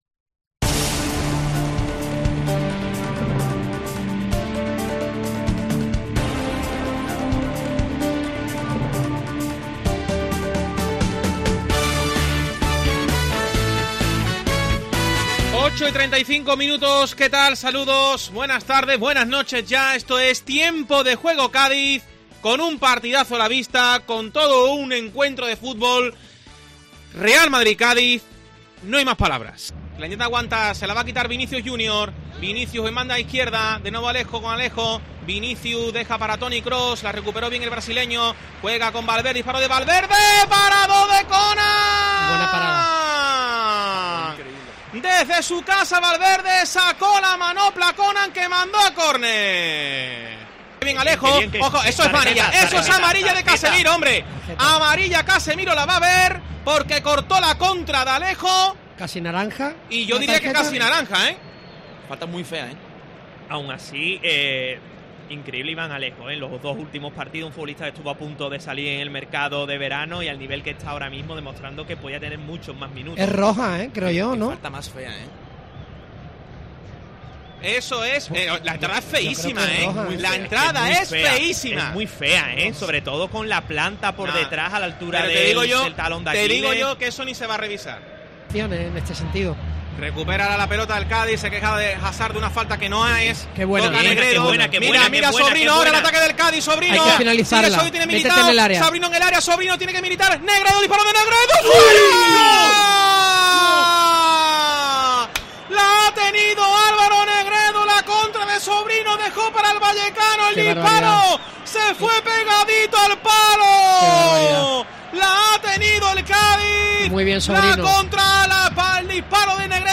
Escucha el resumen sonoro con los mejores momentos del partido en el Santiago Bernabéu en Tiempo de Juego Cádiz
El resumen sonoro del Real Madrid 0-0 Cádiz